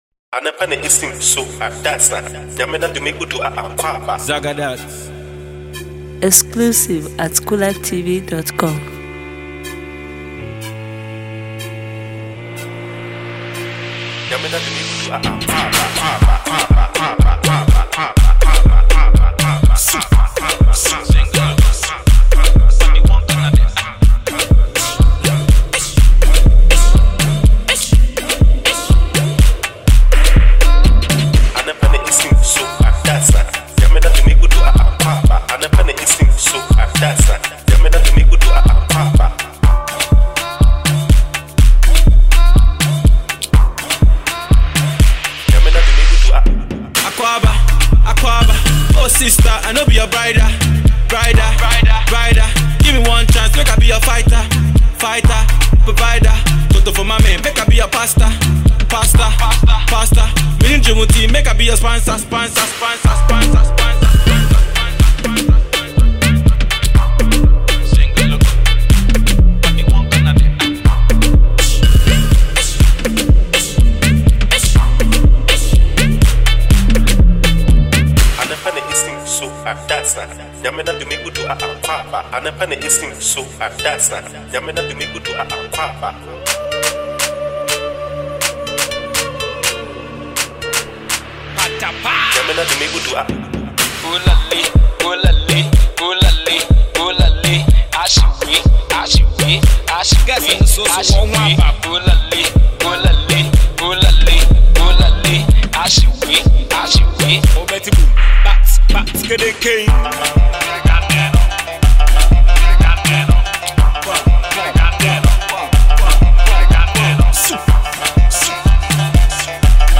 rapper
fast tempo banger